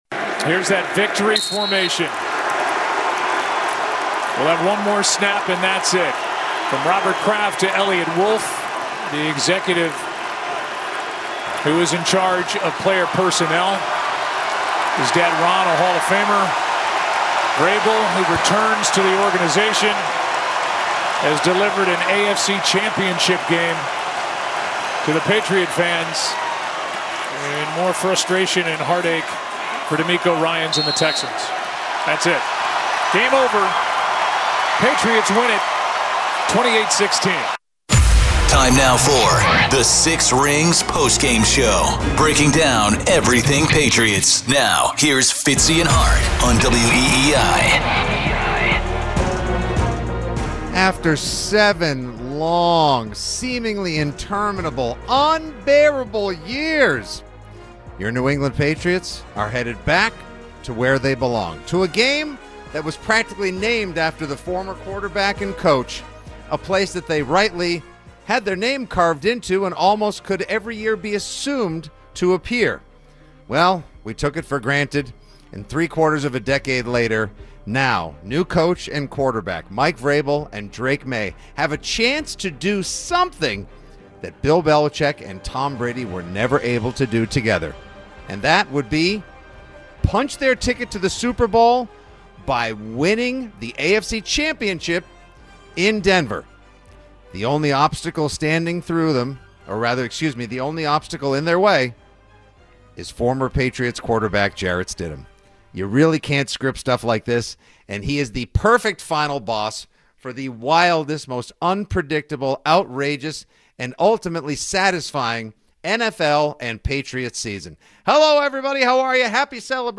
Hear from Patriots Head Coach Mike Vrabel and QB Drake Maye as they meet with the media after the win, and fans as they call in from all corners of New England to celebrate the win.